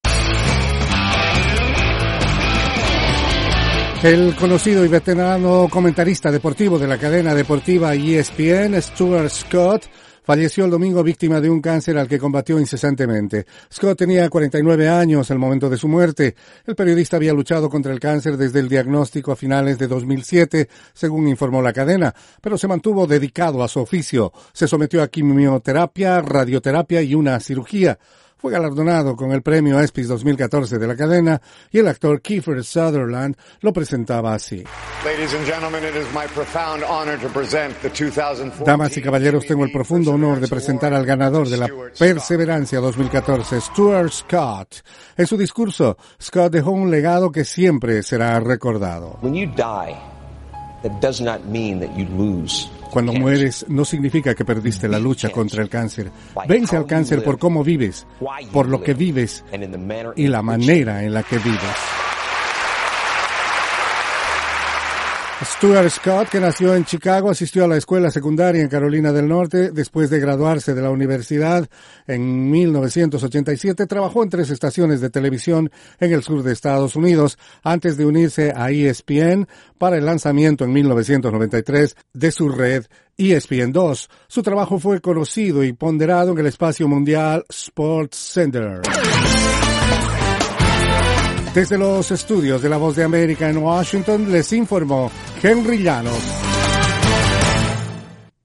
Luego de una lucha incesante contra el cáncer, falleció el domingo el experimentado periodista y comentarista Stuart Scott de la cadena deportiva ESPN. Informa